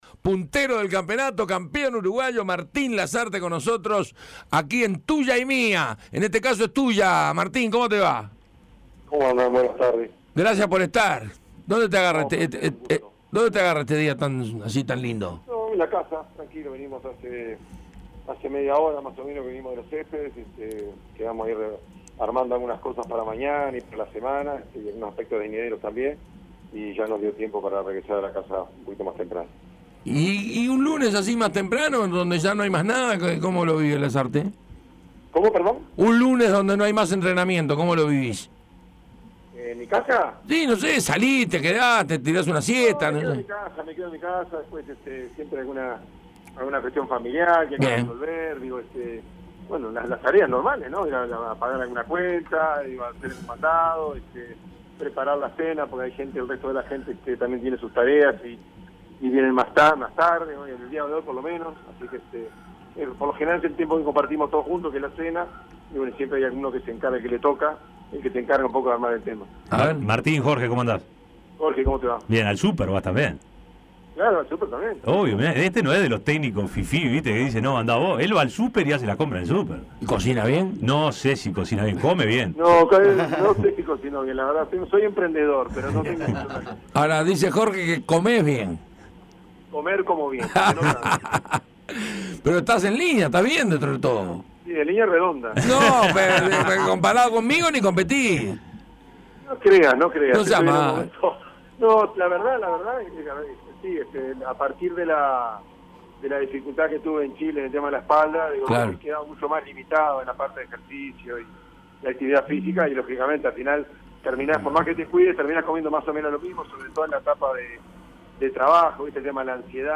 El entrenador de Nacional, Martín Lasarte, habló con el panel de Tuya y Mía respecto al gran arranque de su equipo en el Campeonato Apertura. También se refirió al numeroso plantel que tiene a su disposición y a los objetivos en la Copa Libertadores. Escuchá la entrevista completa.